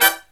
HIGH HIT10-R.wav